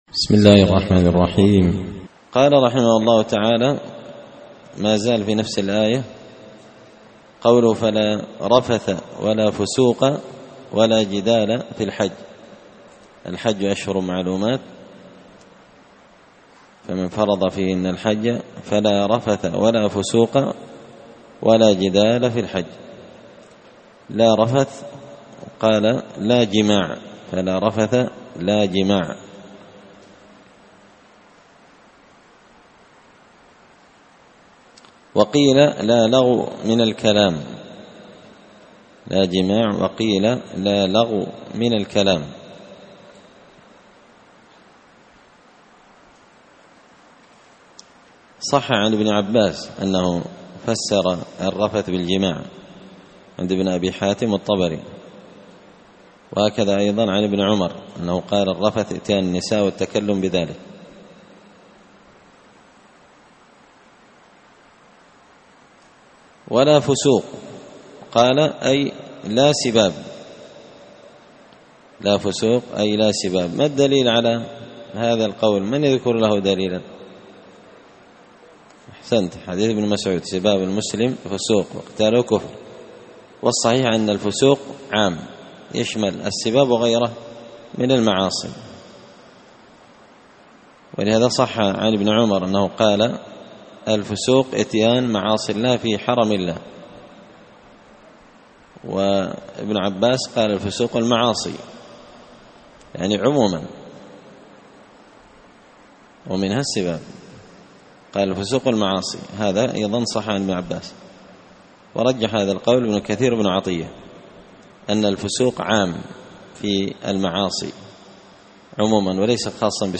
تفسير مشكل غريب القرآن ـ الدرس 37
دار الحديث بمسجد الفرقان ـ قشن ـ المهرة ـ اليمن